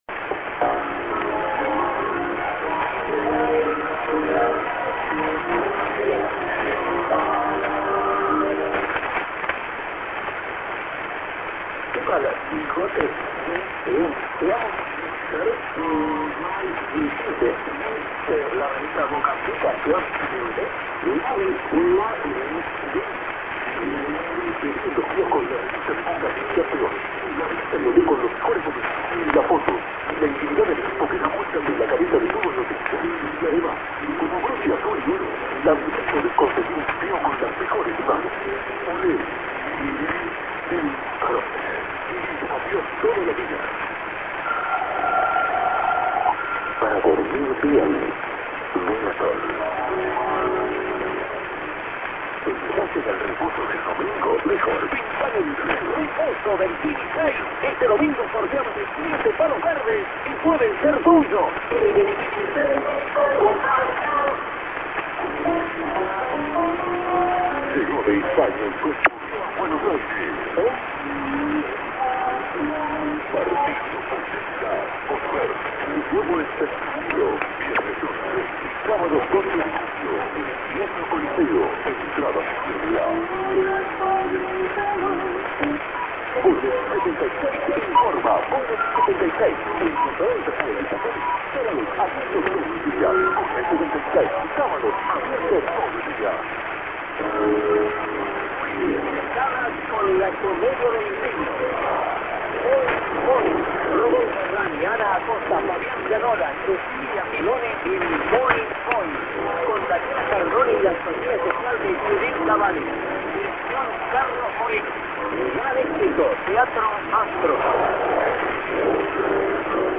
->prog(man)->over the TS-> No ID